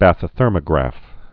(băthĭ-thûrmə-grăf)